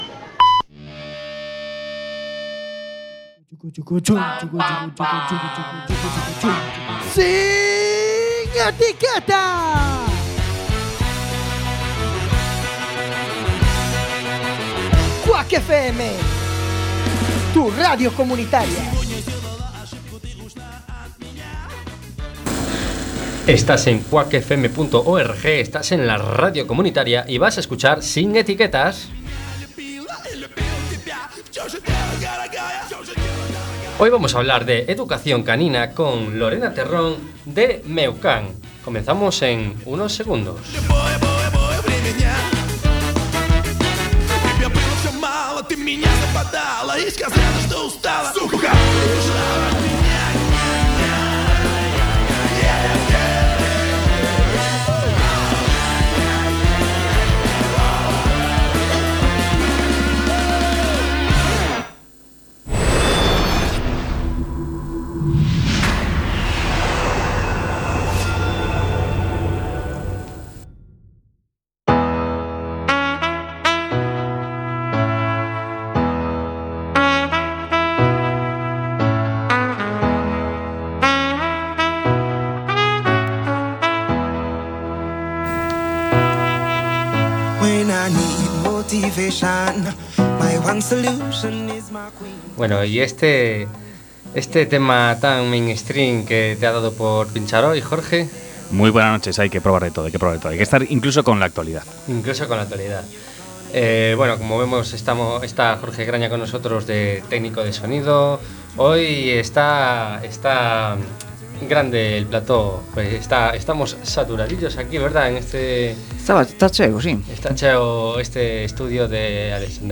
Magazine con entrevistas distendidas y frescas.